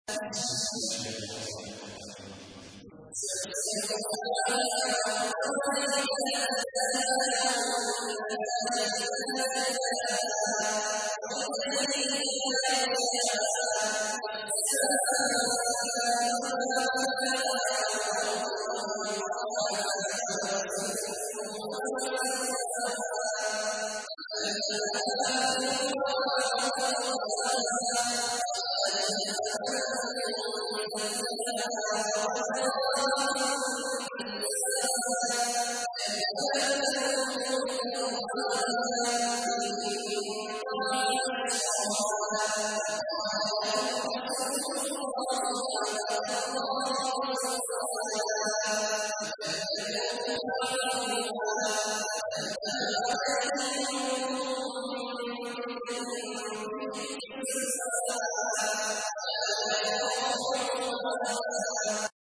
تحميل : 91. سورة الشمس / القارئ عبد الله عواد الجهني / القرآن الكريم / موقع يا حسين